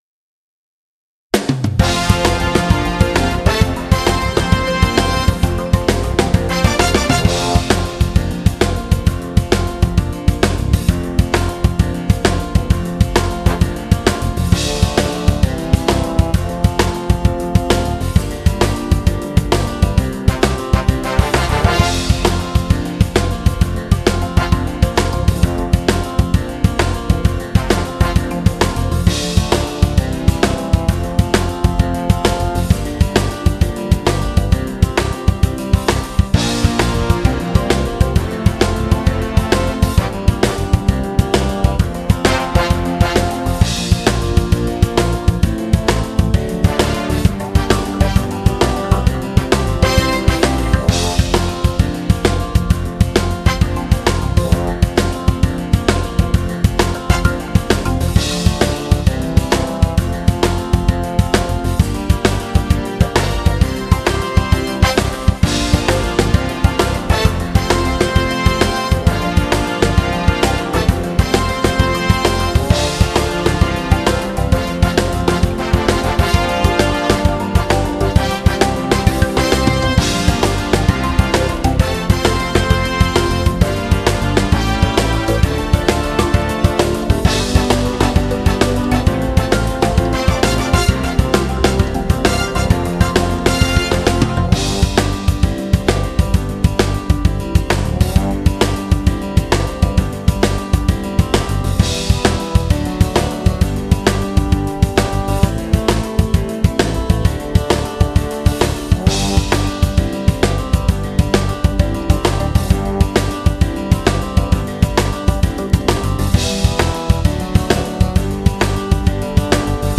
Genere: Hully gully